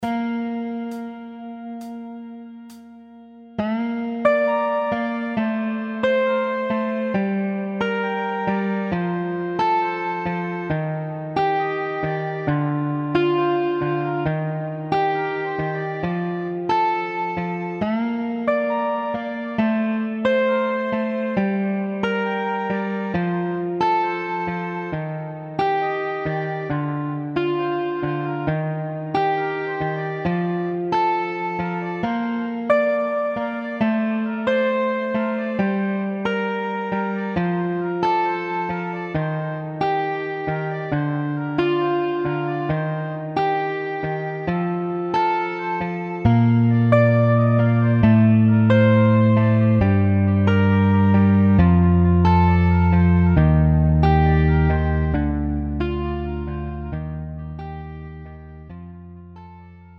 음정 여자키
장르 pop 구분 Pro MR